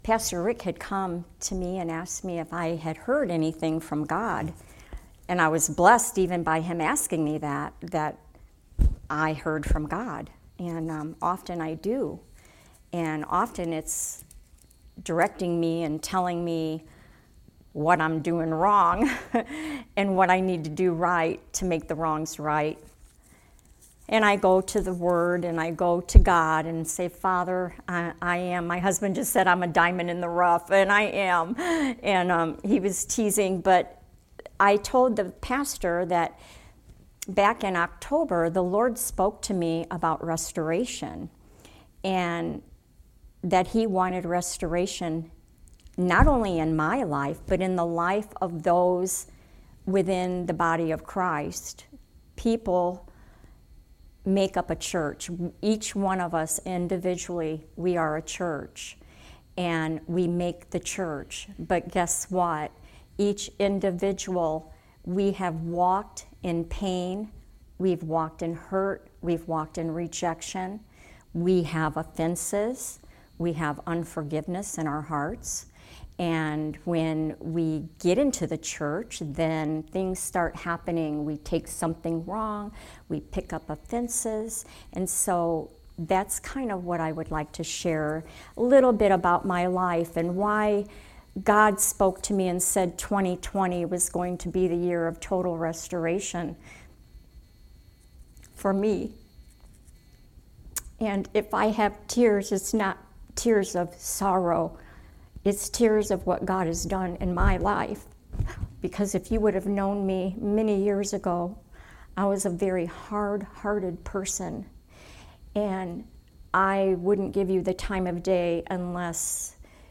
Sermon messages available online.
Service Type: Sunday Teaching